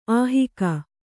♪ āhika